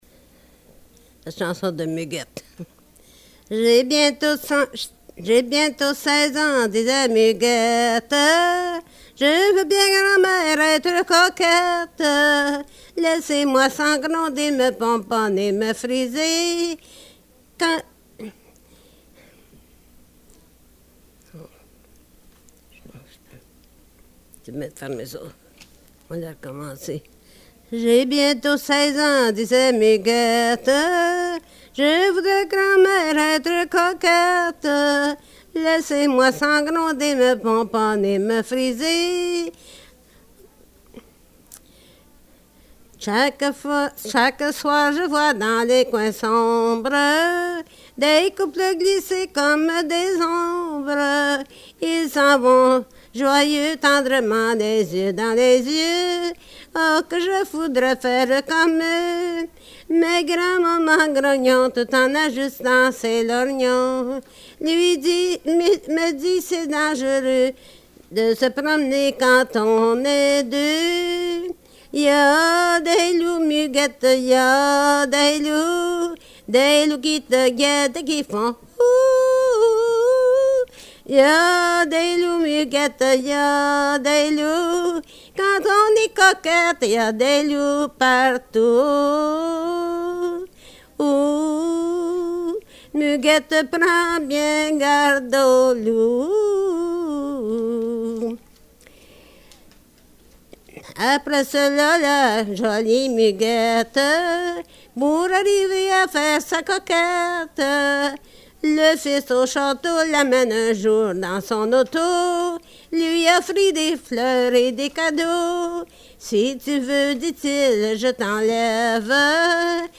Folk Songs, French--New England
a French pop song